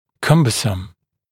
[‘kʌmbəsəm][‘камбэсэм]громоздкий, обременительный, затруднительный